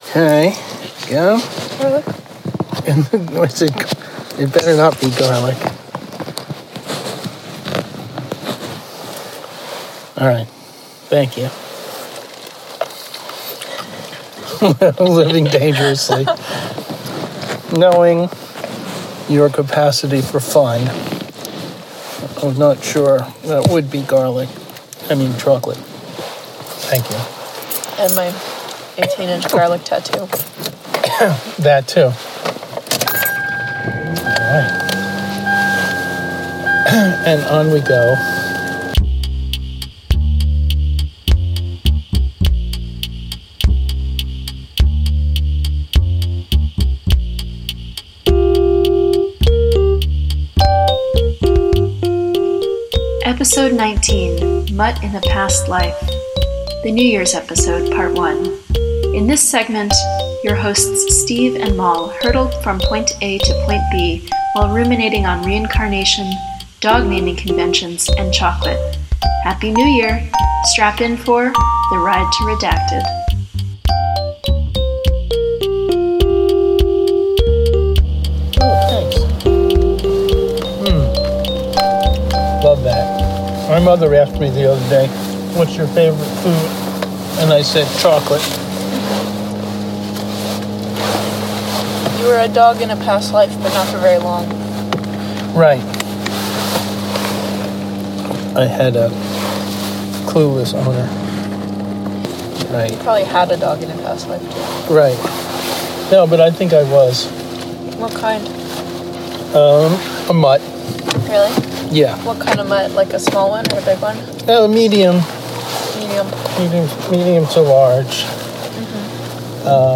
Listeners, the recording of our ride from Point A to Point B on December 21, 2025, was unusually productive. On that ride, your hosts were even more conversationally lively than usual, if you can believe that.